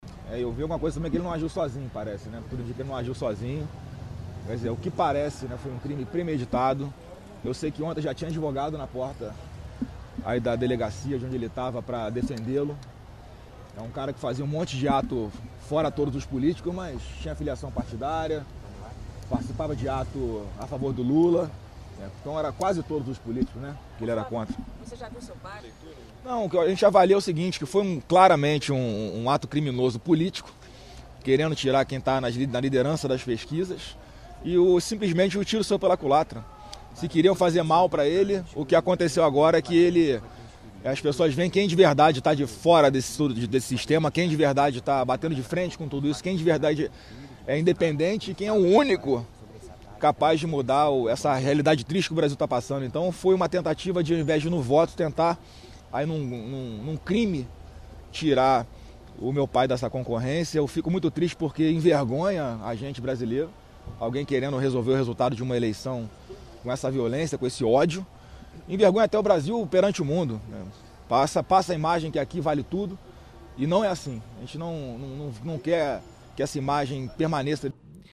Mais cedo, a reportagem conversou com os filhos do candidato.